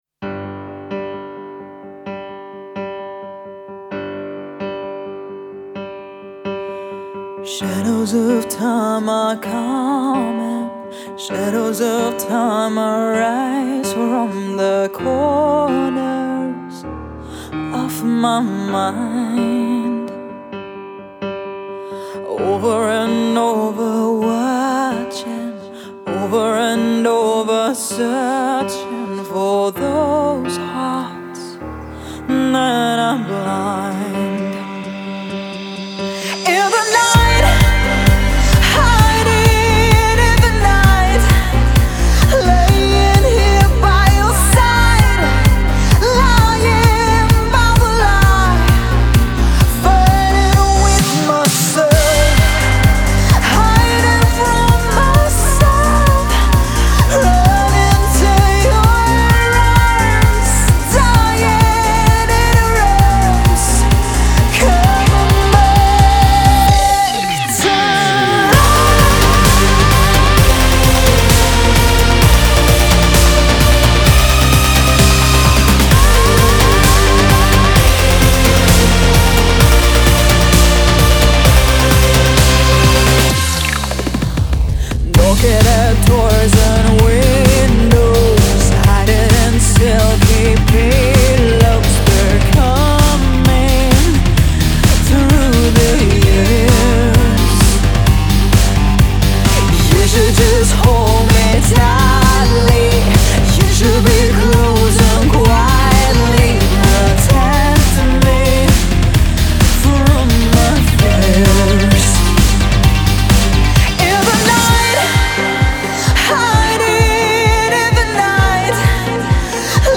Главная » Файлы » Pop